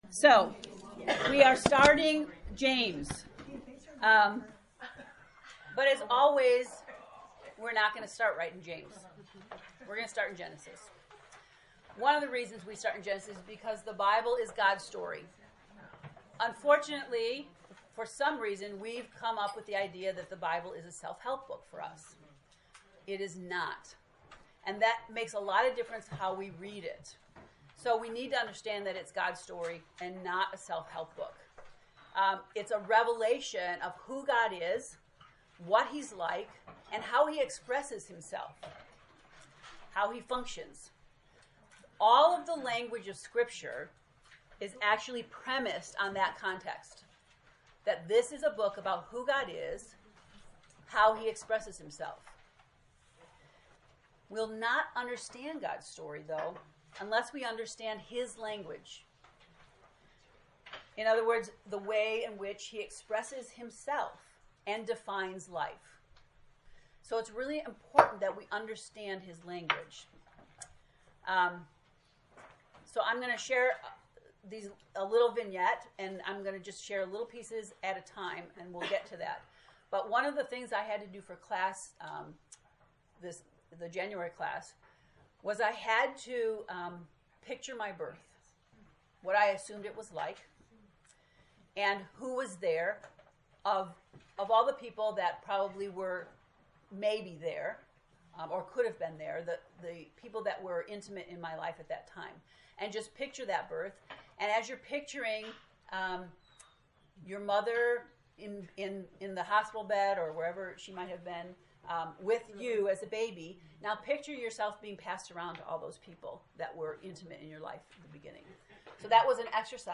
JAMES lesson 0
james-lect-0.mp3